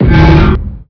pain2.wav